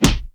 PUNCH  2.WAV